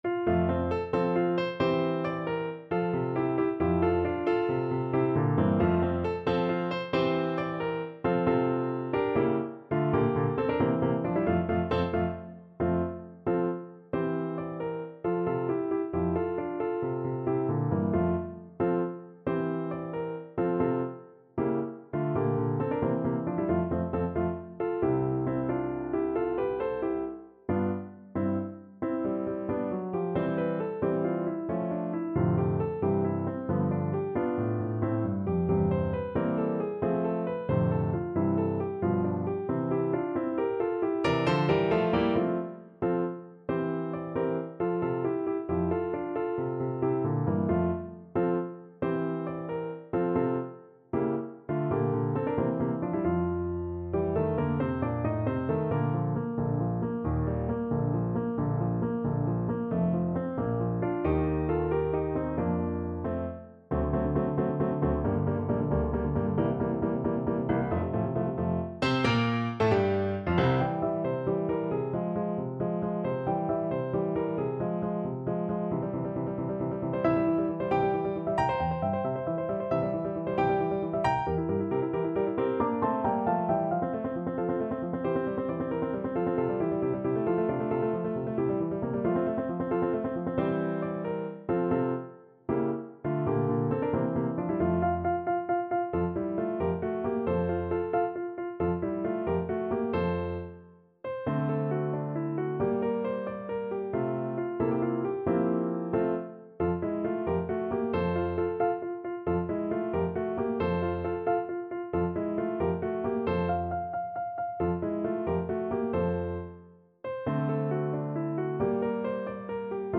Play (or use space bar on your keyboard) Pause Music Playalong - Piano Accompaniment Playalong Band Accompaniment not yet available transpose reset tempo print settings full screen
. = 90 Allegretto vivace
6/8 (View more 6/8 Music)
Classical (View more Classical Trumpet Music)